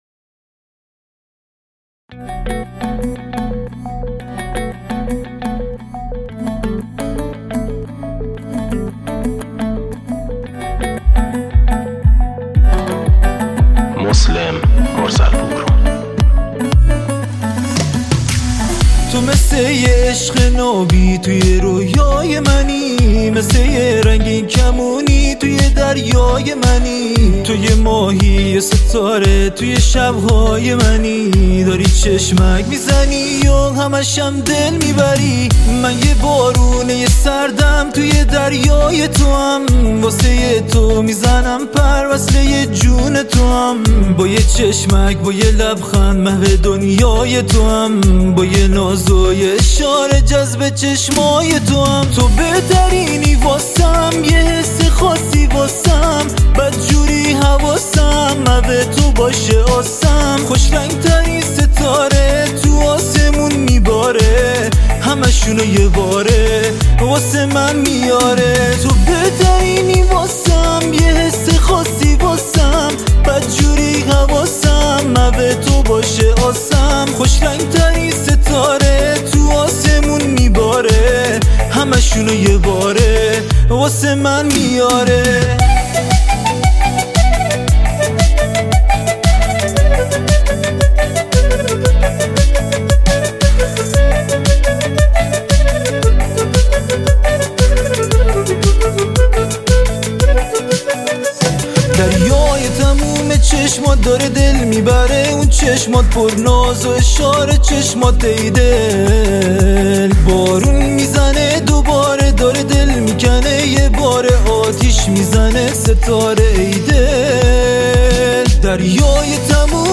موسیقی
آهنگهای پاپ فارسی